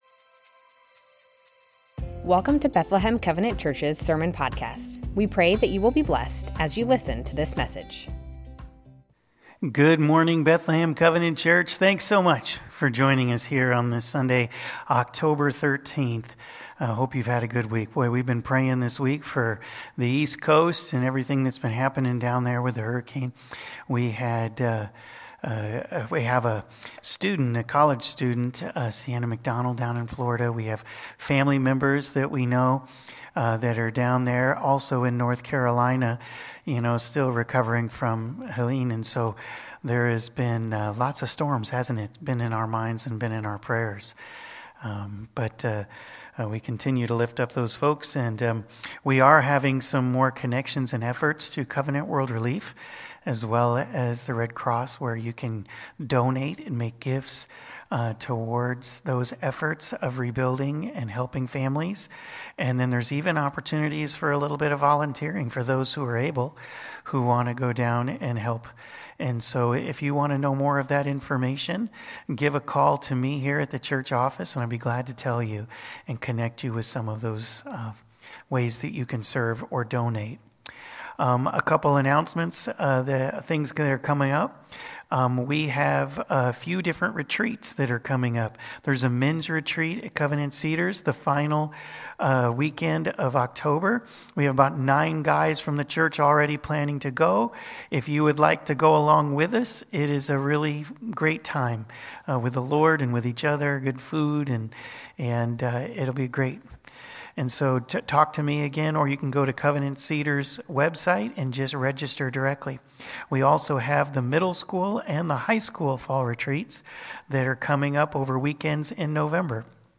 Bethlehem Covenant Church Sermons Living to please God - 1 Thessalonians 5:16-28 Oct 13 2024 | 00:37:13 1x 00:00 / 00:37:13 Subscribe Share